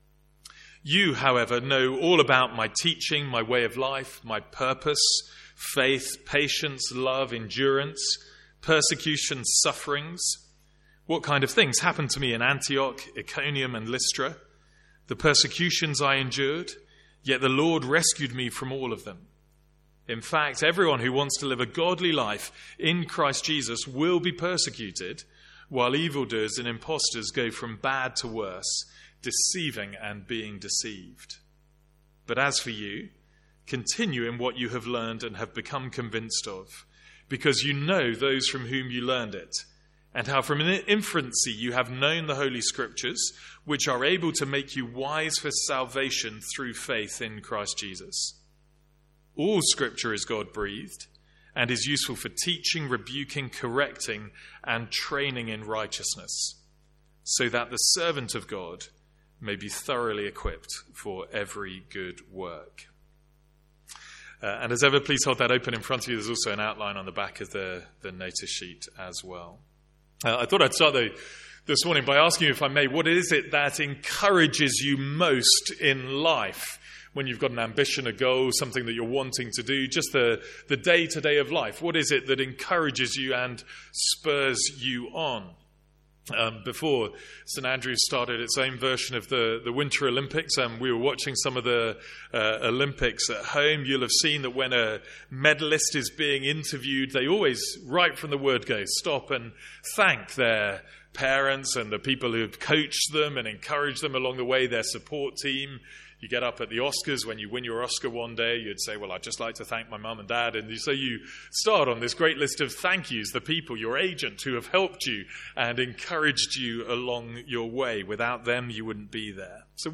Sermons | St Andrews Free Church
From our morning series in 2 Timothy.